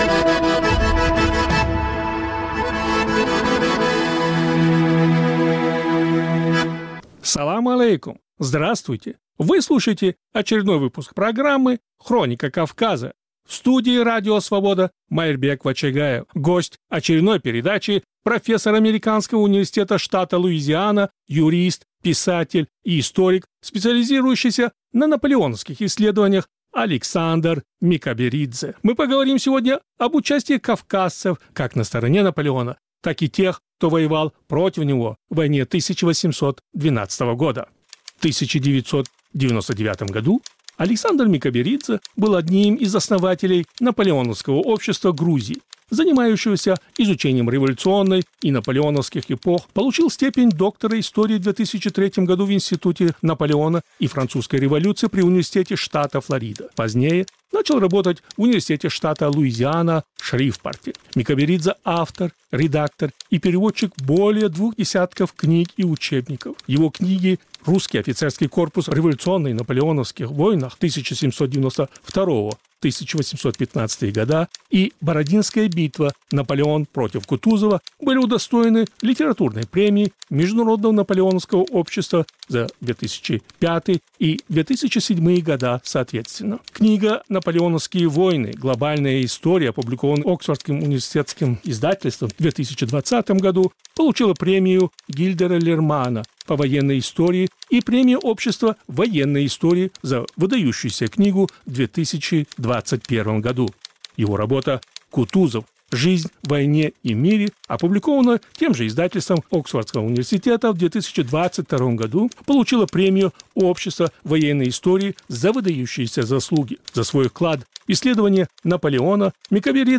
Повтор эфира от 21 января 2024 года.